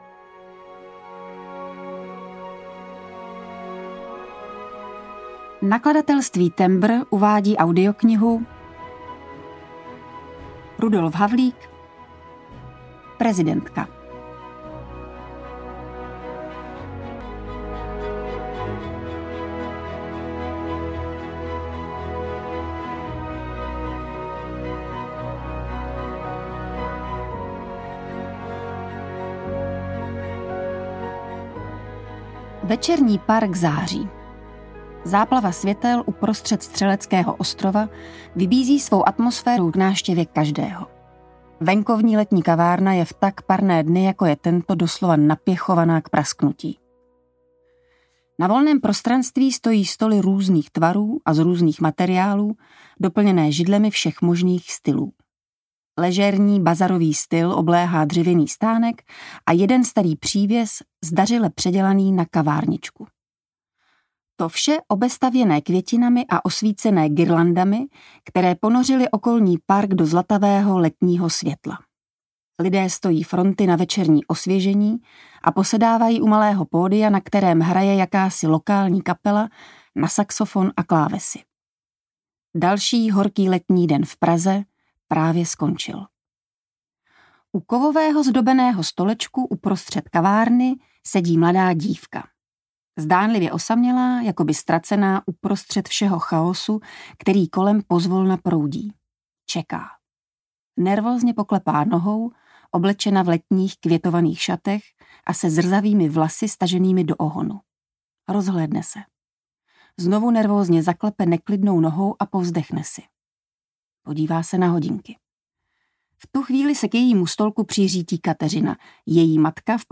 Prezidentka audiokniha
Ukázka z knihy
Čte Aňa Geislerová
Natočeno ve studiu S Pro Alfa CZ
• InterpretAňa Geislerová